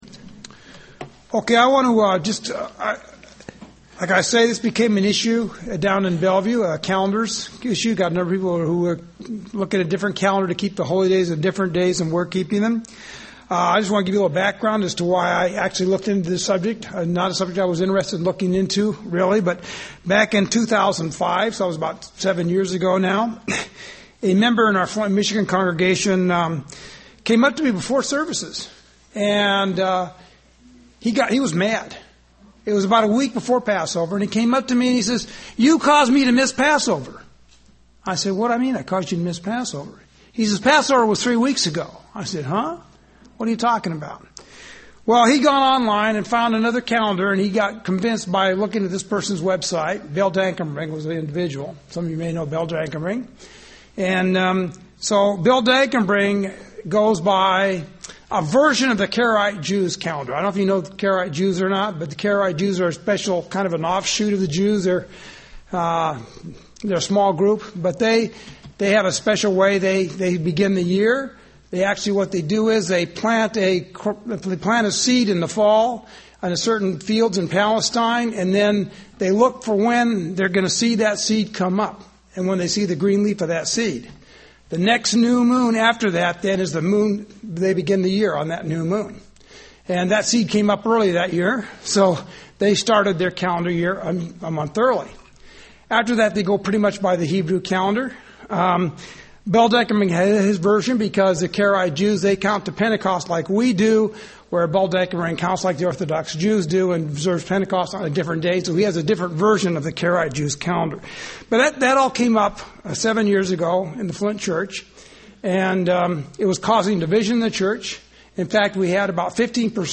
Bible Study on the calender.